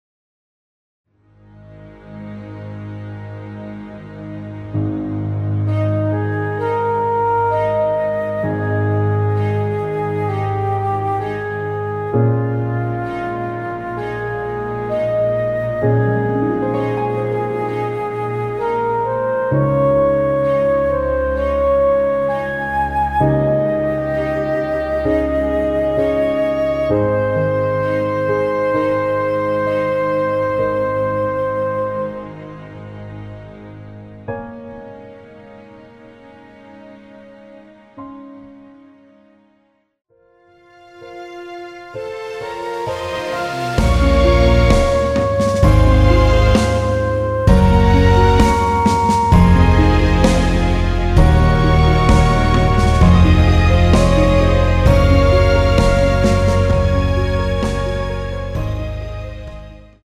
원키에서(+4)올린 MR입니다.
G#
앞부분30초, 뒷부분30초씩 편집해서 올려 드리고 있습니다.
중간에 음이 끈어지고 다시 나오는 이유는